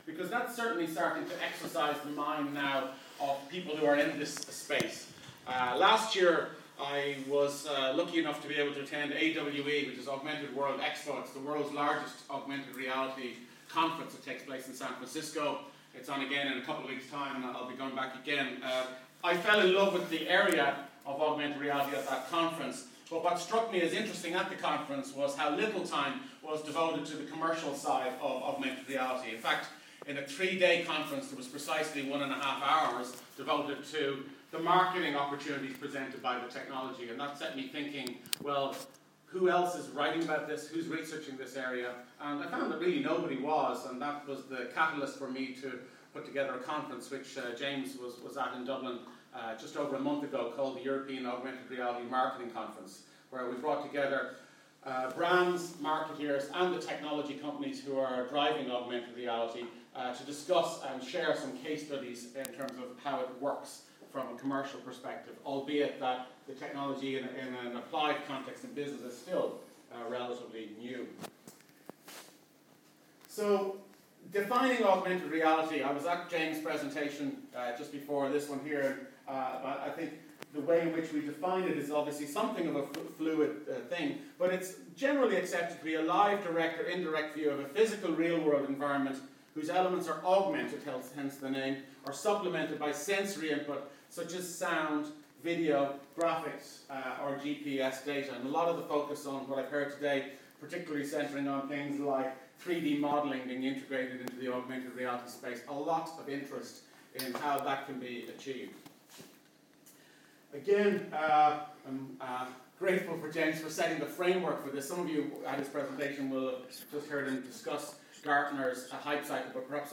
speaks at #3dcamp